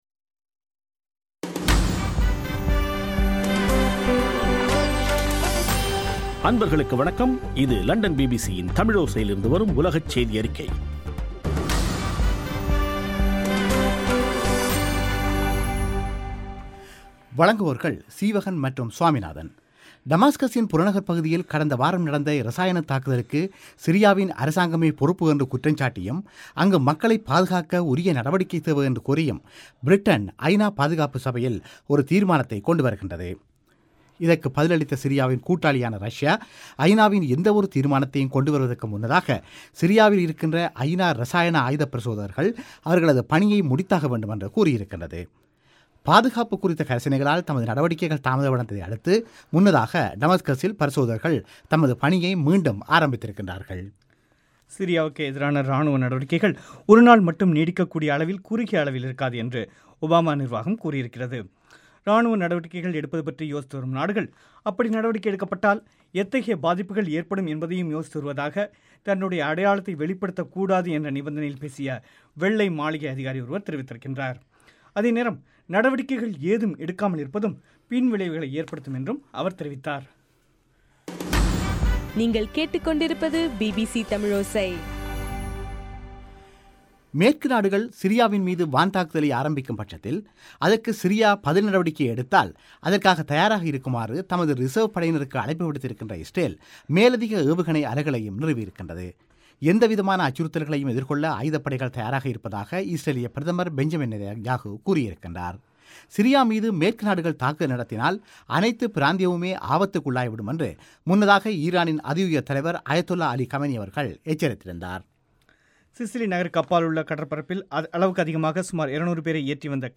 ஆகஸ்ட் 28 பிபிசியின் உலகச் செய்திகள்